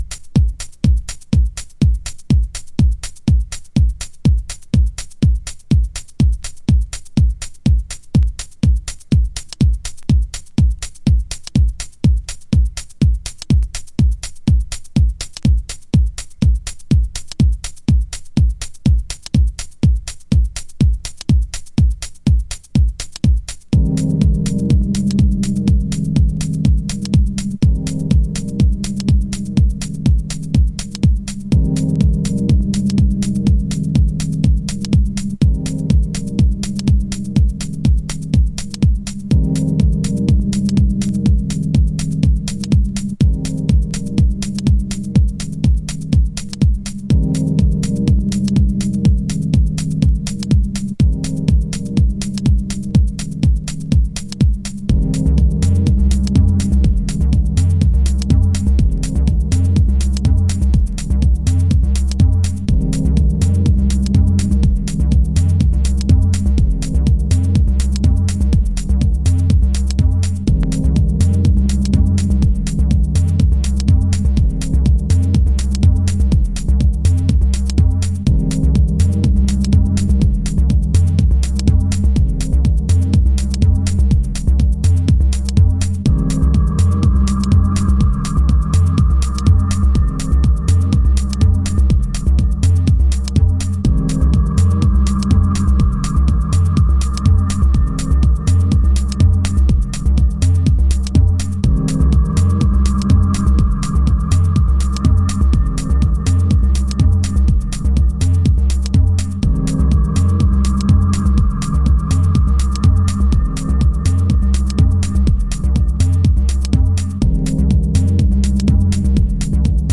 Detroit House / Techno
美しすぎる空間性。